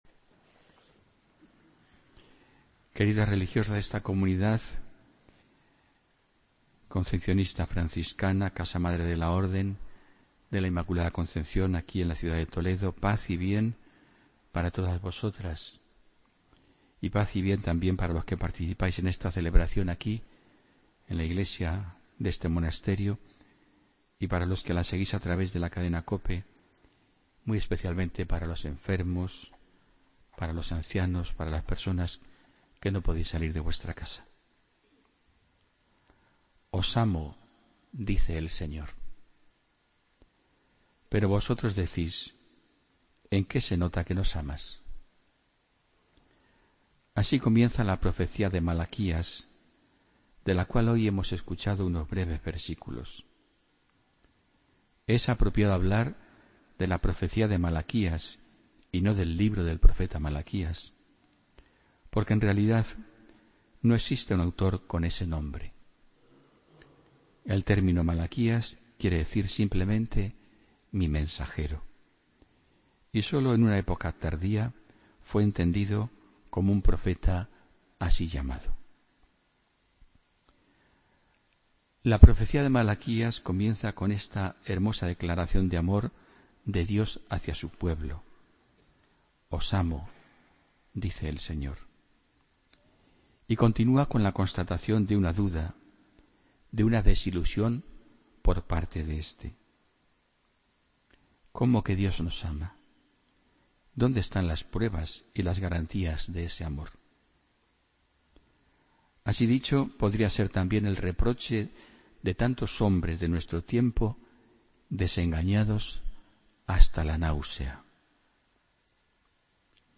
Homilía del domingo 13 de noviembre de 2016